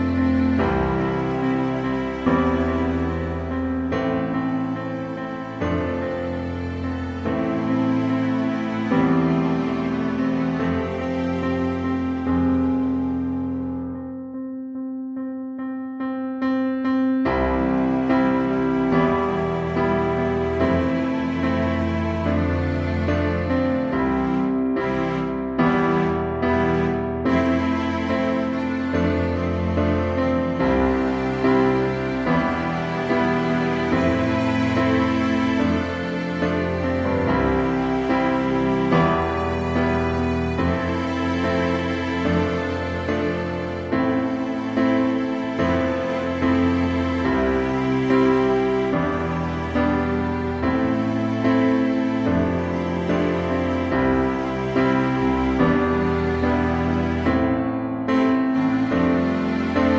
no Backing Vocals Rock 3:19 Buy £1.50